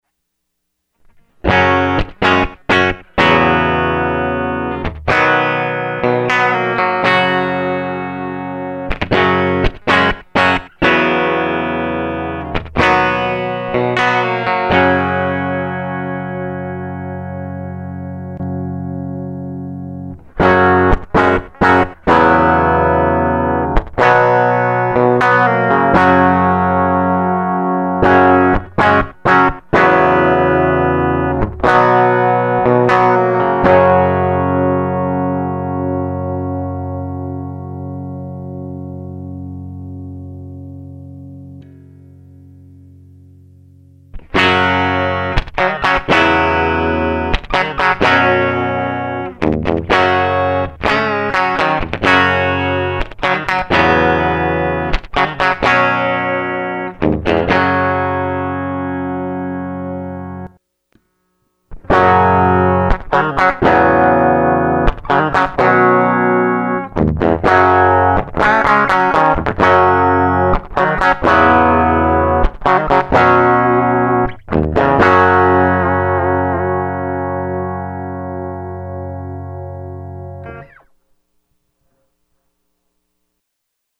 Quick demo of parallel vs. series wiring on a Duncan Hot Rails Strat bridge pickup.
Parallel first, Series 2nd, Parallel again 3rd, Series 4th. I added a little doubler/slap effect to try and make it sound less crappy, the ONLY thing that changes during the clip is parallel/series.
This first clip is the guitar direct (no EQ) with some slight compression to smooth out the level (series is quite a bit hotter in output).  Recorded direct the tonal difference is pretty dramatic.
HotRailsDirectParallelSeriesClean.mp3